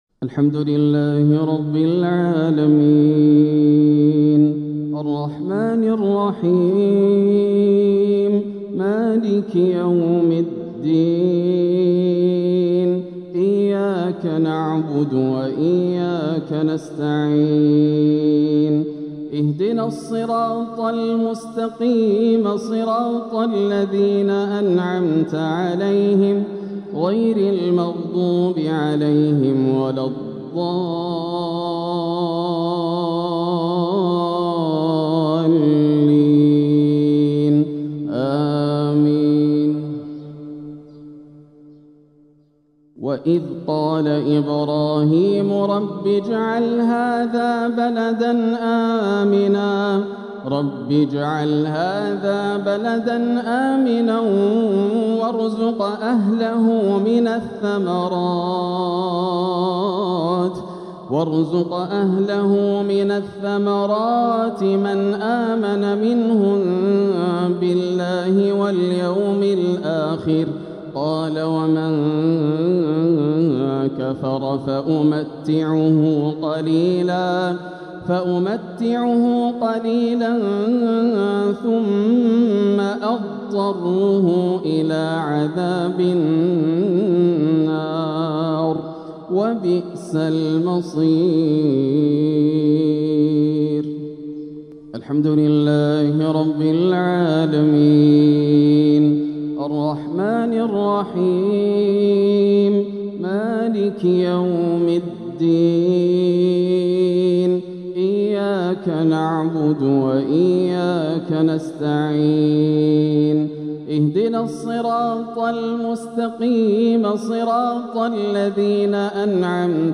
العقد الآسر لتلاوات الشيخ ياسر الدوسري تلاوات شهر ربيع الآخر عام 1447هـ من الحرم المكي > سلسلة العقد الآسر من تلاوات الشيخ ياسر > المزيد - تلاوات ياسر الدوسري